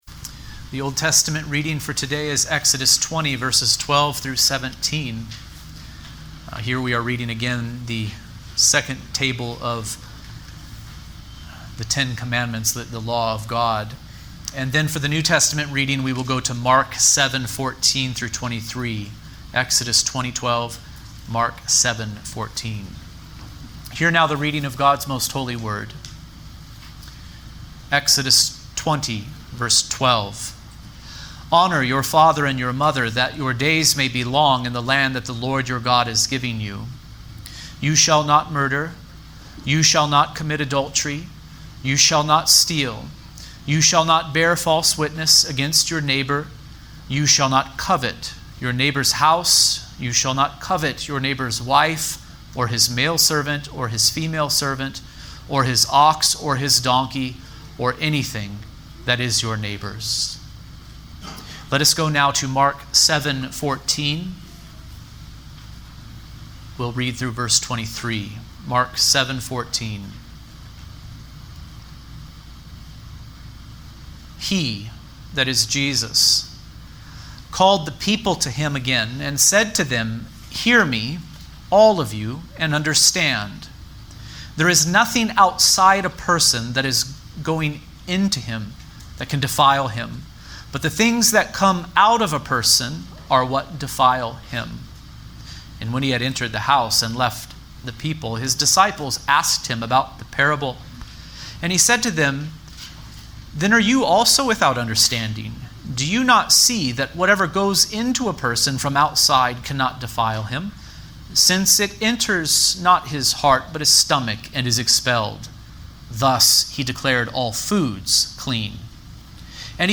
The Tenth Commandment | SermonAudio Broadcaster is Live View the Live Stream Share this sermon Disabled by adblocker Copy URL Copied!